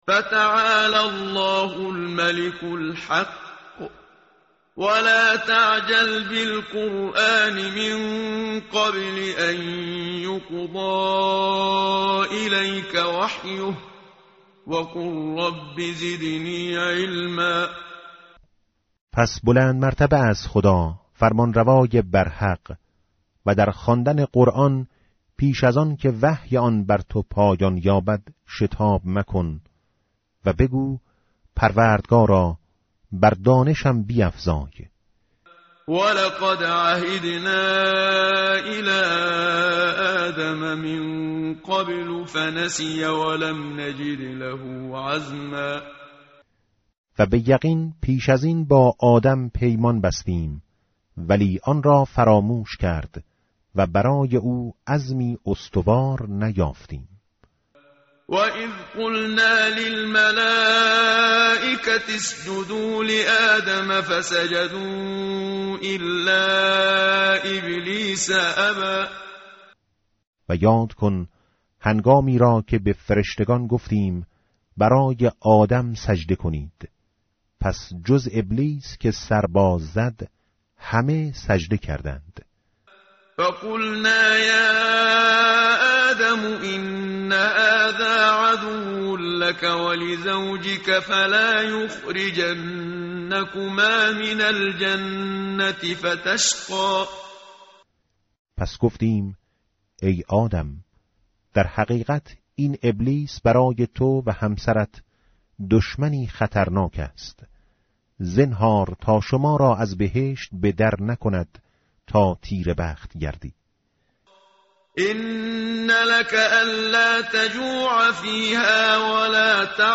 متن قرآن همراه باتلاوت قرآن و ترجمه
tartil_menshavi va tarjome_Page_320.mp3